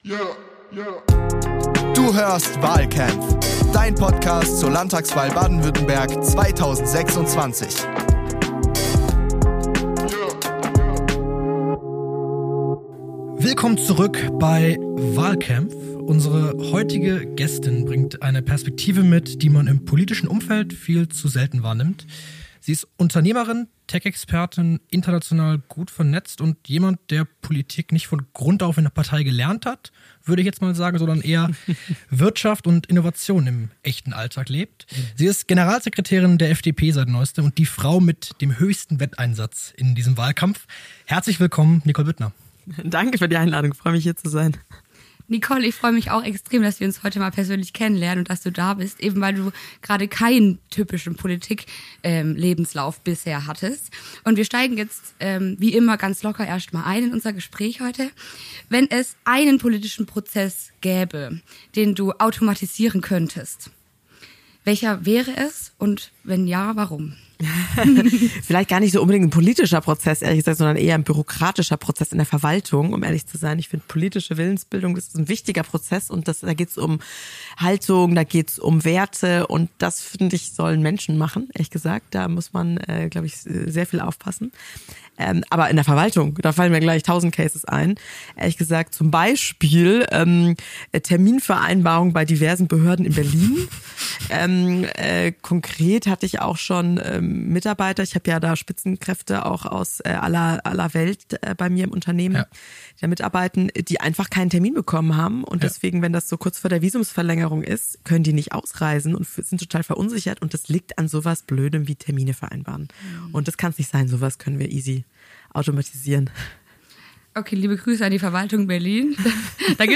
- Ein Gespräch voller Energie, Zukunftsoptimismus und dem klaren Plan, Baden-Württemberg neu zu programmieren.